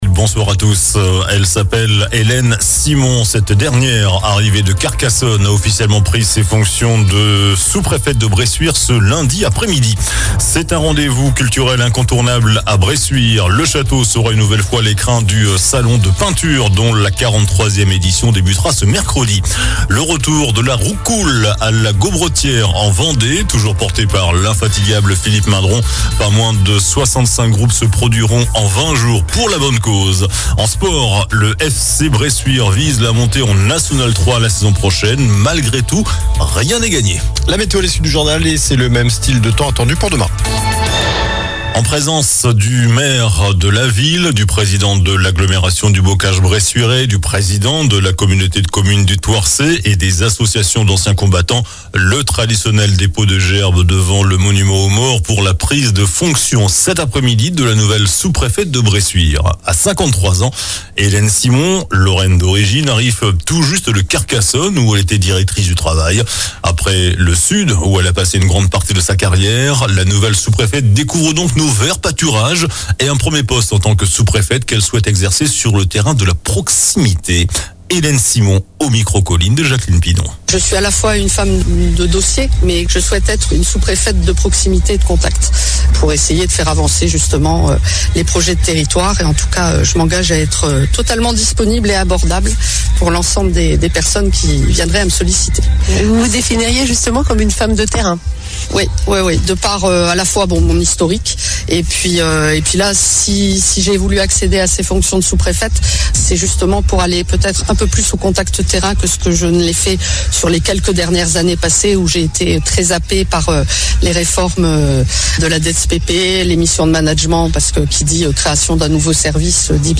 JOURNAL DU LUNDI 29 AVRIL ( SOIR )